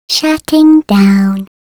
Play, download and share shutting down… original sound button!!!!
shutting-down.mp3